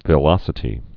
(vĭ-lŏsĭ-tē)